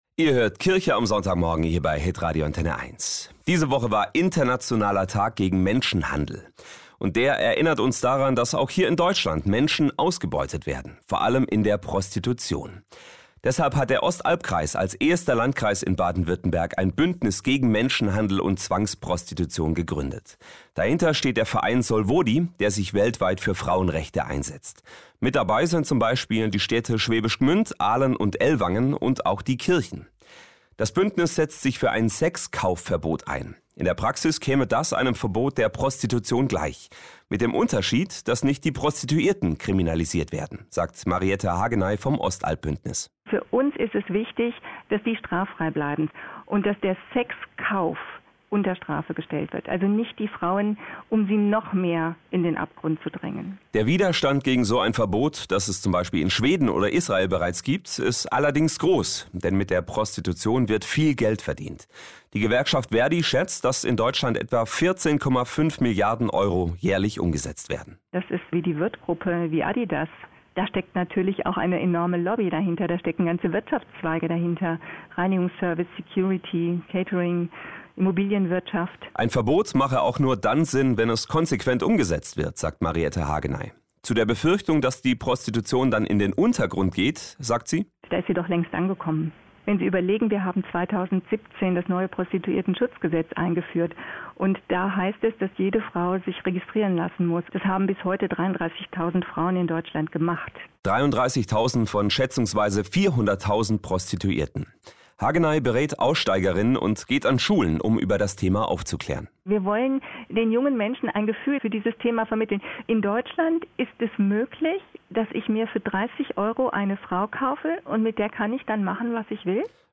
20_08_02_Interview Internationaler Tag gegen Menschenhandel